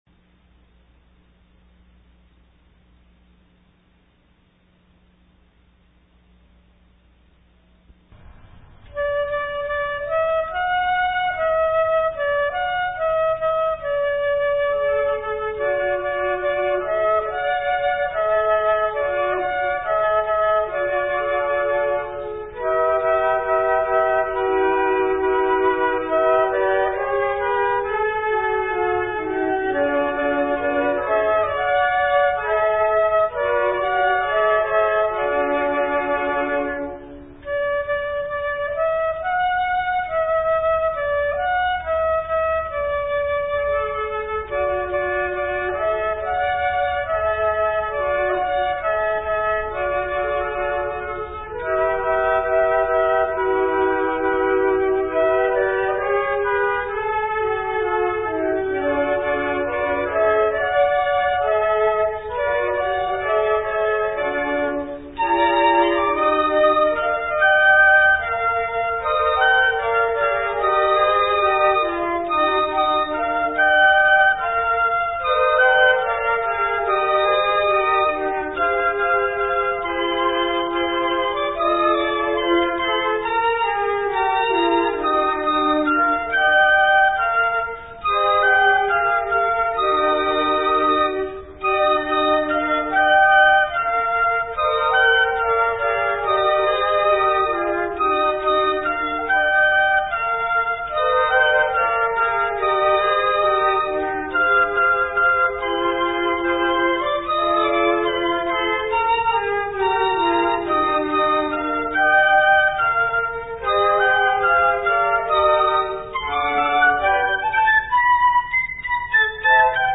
D, Eb, G
Fl choir; ww quartet
Song (ternary)
(sc, pic, fl1, fl2, fl3, opt 4th fl/alto/bass flutes)